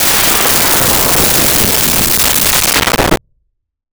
Alien Wierdness Descending 03
Alien Wierdness Descending 03.wav